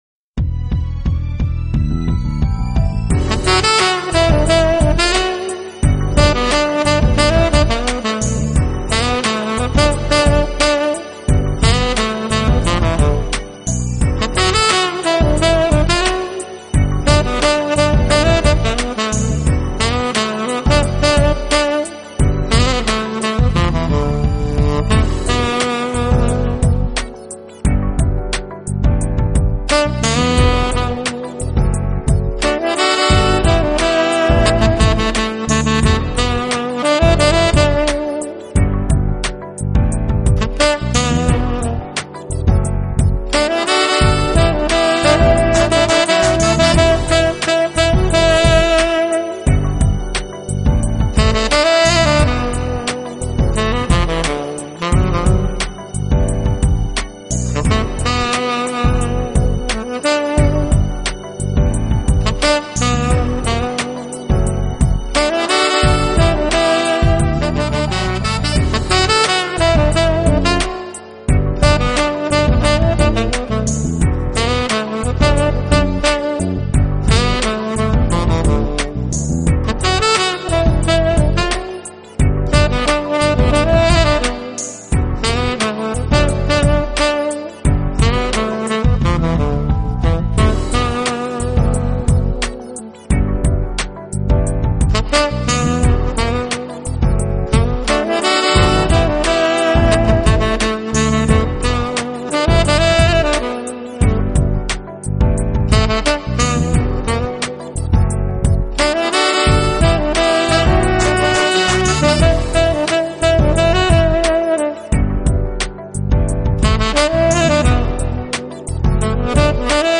Genre................: Jazz